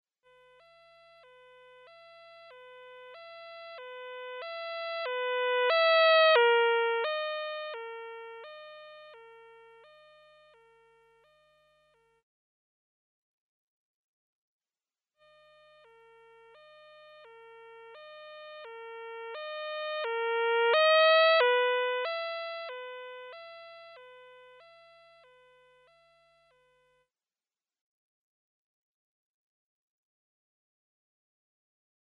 You are here: Home » Lehre » Hörbeispiele (Musikalische Akustik) » 0123dopplereffekt.mp3 » View File
0123dopplereffekt.mp3